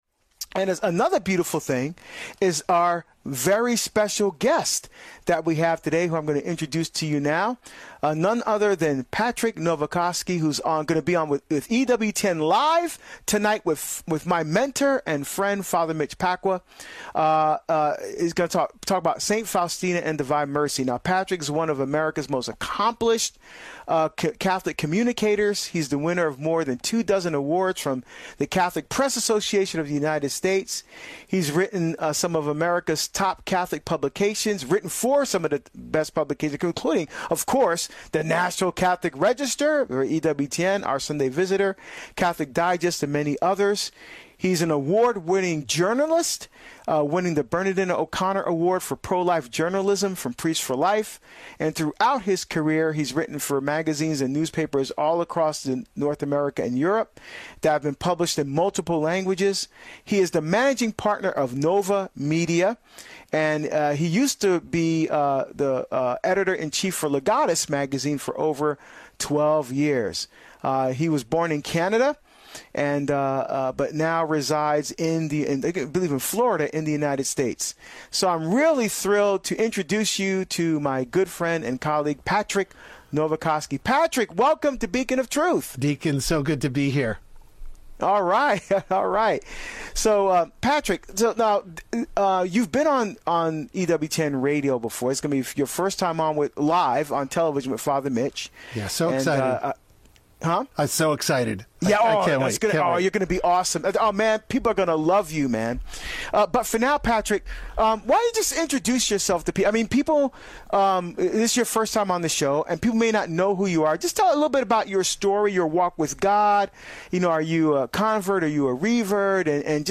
LISTEN TO THE ENTIRE INTERVIEW HERE (34 minutes 52 seconds) WATCH THE INTERVIEW HERE (Jump to 19 minutes 00 seconds)